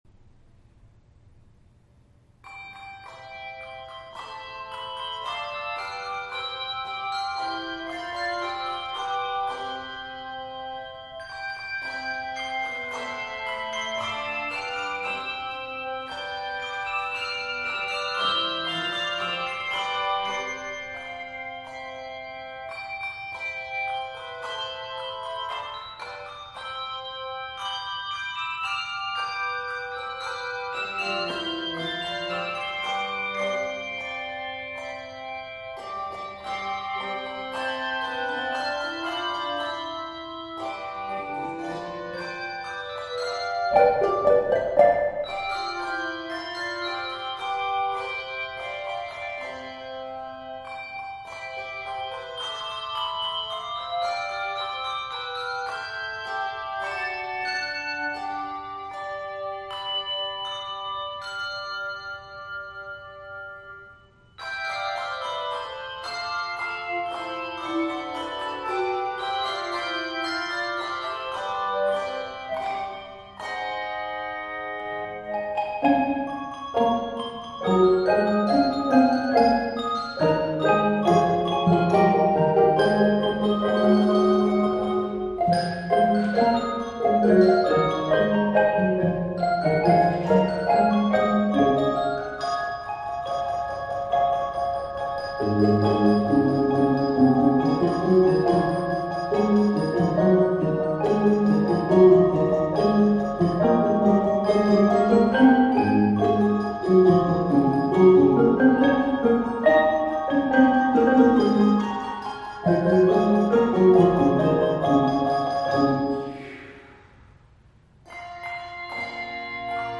arranged for handbells